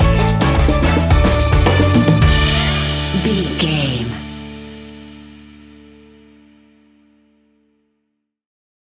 An upbeat and uptempo piece of calypso summer music.
That perfect carribean calypso sound!
Ionian/Major
steelpan
drums
brass
guitar